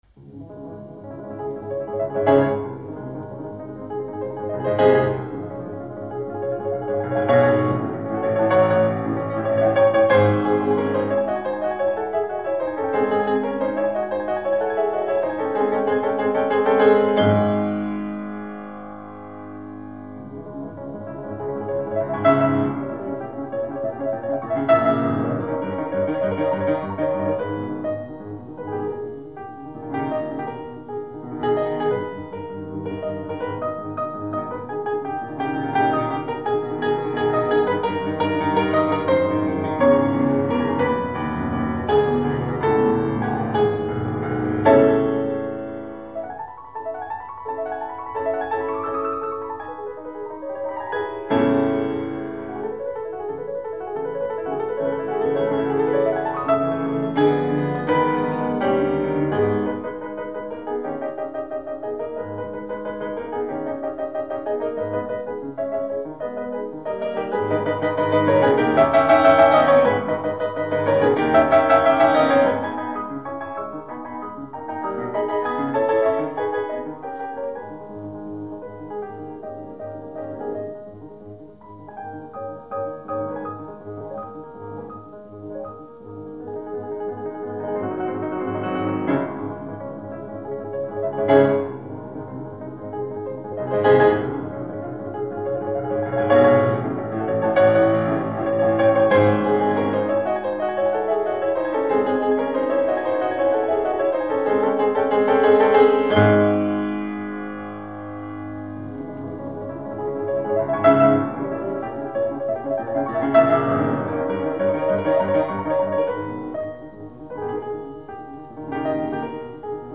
классической мелодии